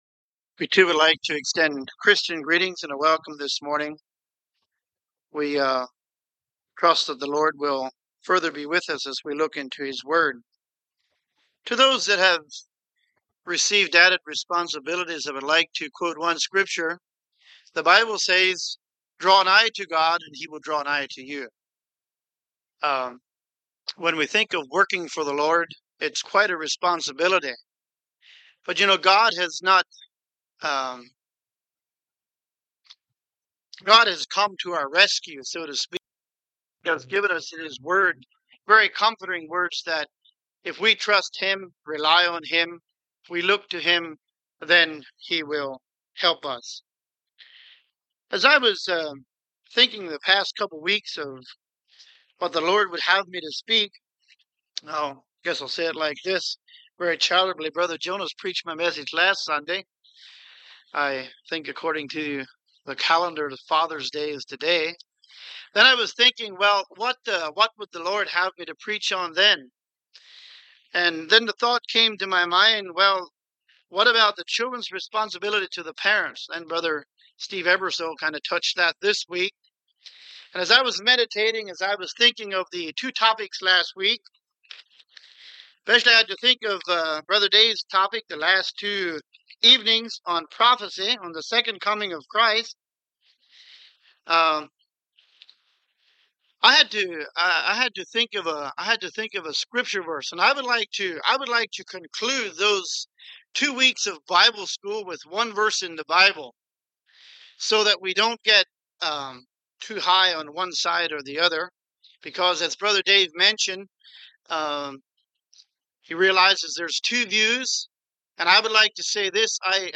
John 3:16 Service Type: Sunday Morning Topics: Salvation « Waiting as Political Activism